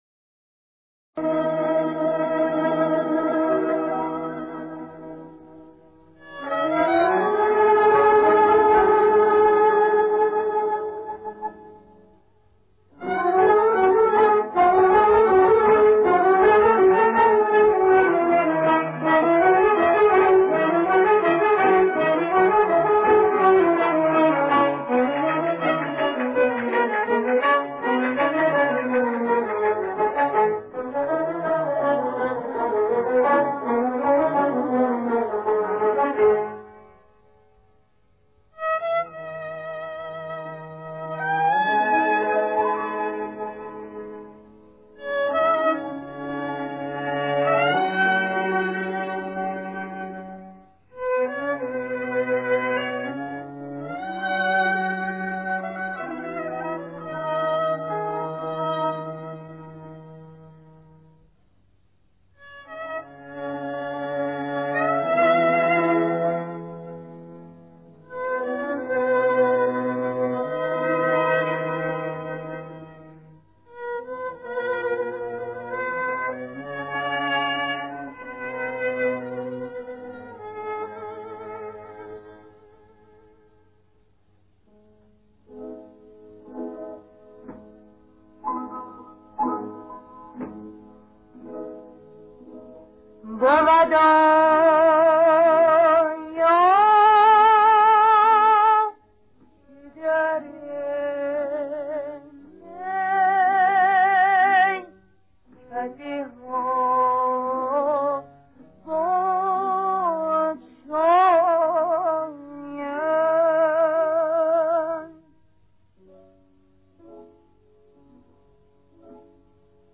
در مایه: دشتی